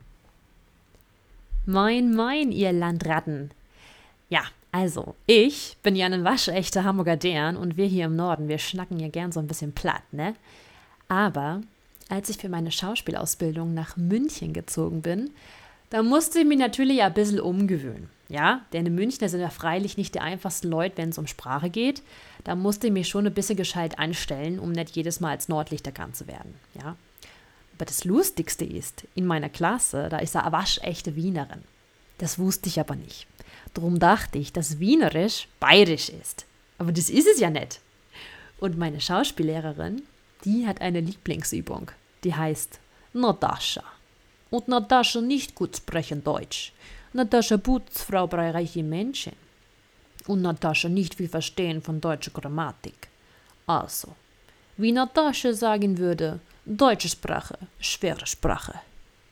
Sprachproben
Dialekte